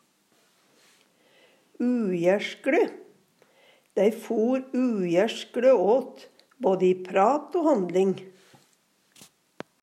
DIALEKTORD PÅ NORMERT NORSK ujærskle ufin, ikkje bra Eksempel på bruk Dei for uærskle åt både i oL o handling.